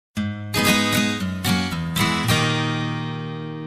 Категория: SMS рингтоны | Теги: SMS рингтоны, GGG, гитара